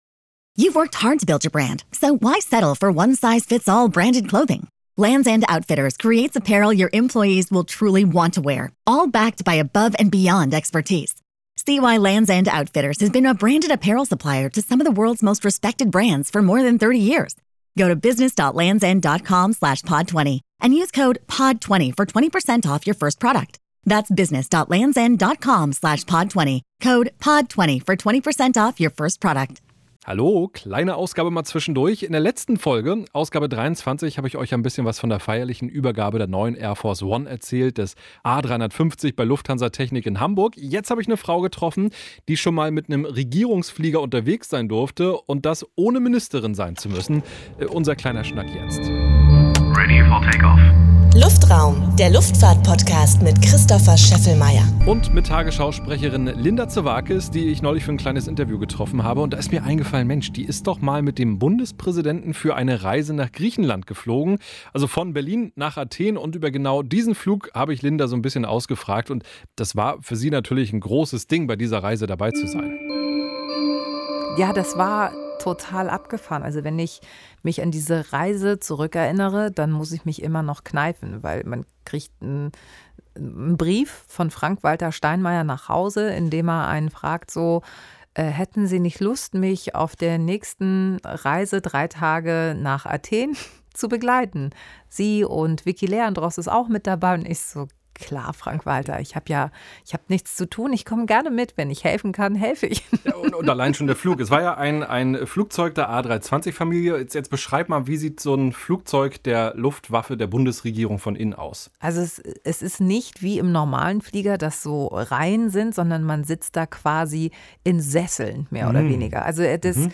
Das Gespräch gibt es hier, in einem